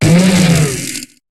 Cri de Lampéroie dans Pokémon HOME.